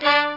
Horns Sound Effect
horns.mp3